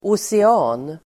Ladda ner uttalet
Uttal: [ose'a:n]